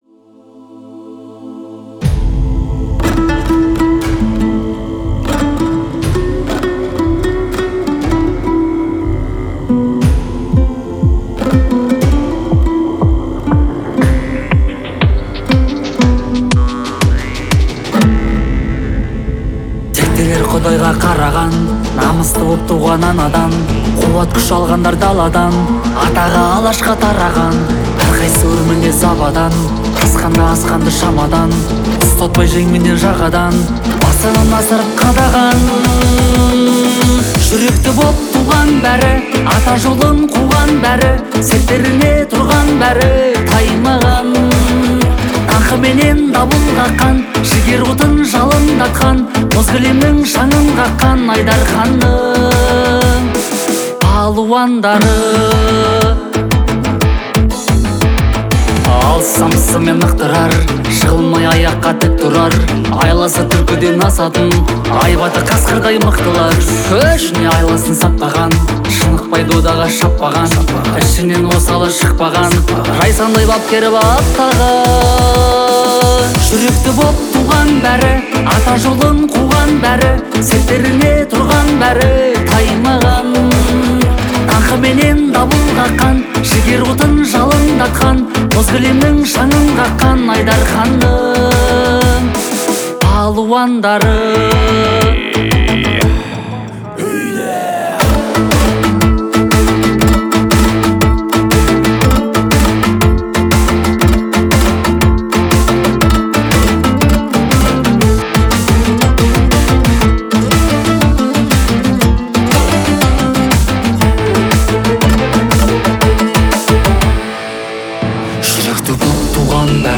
это песня в жанре казахского фольклора